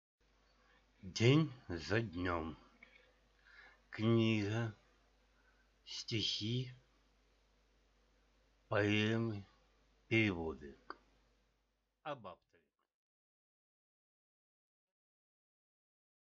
Аудиокнига День за днём | Библиотека аудиокниг